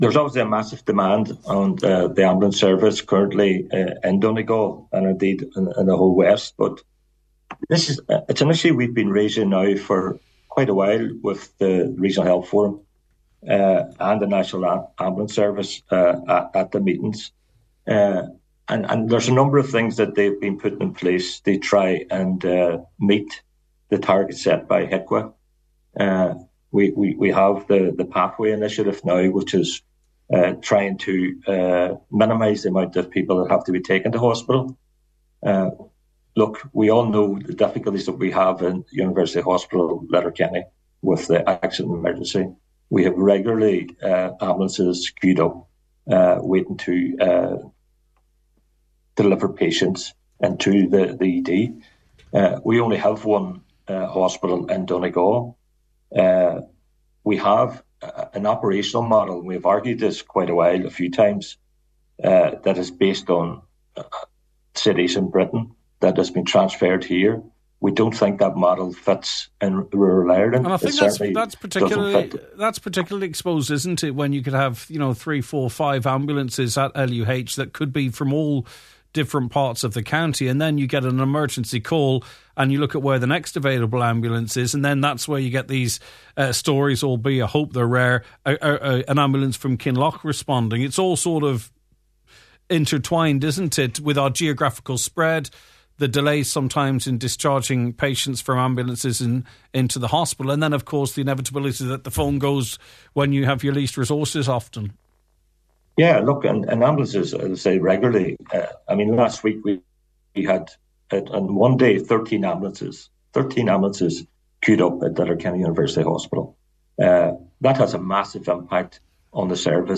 Cllr Gerry McMonagle is Chair of the Regional Health Forum.